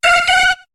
Cri de Natu dans Pokémon HOME .